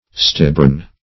stibborn - definition of stibborn - synonyms, pronunciation, spelling from Free Dictionary Search Result for " stibborn" : The Collaborative International Dictionary of English v.0.48: Stibborn \Stib"born\, a. Stubborn.